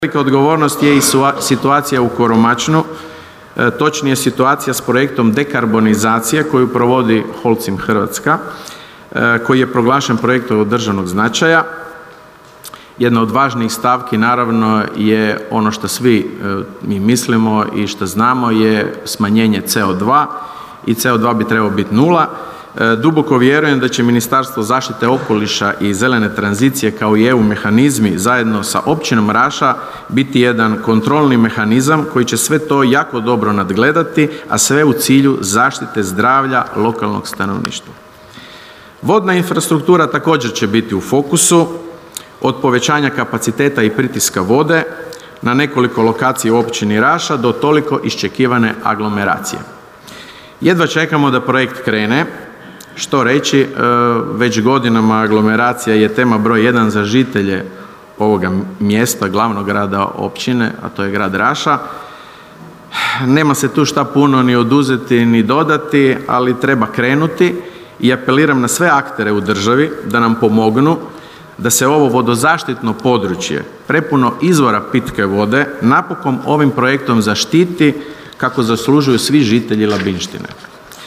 Svečanom sjednicom Općinskog vijeća Općina Raša jučer je proslavila svoj dan.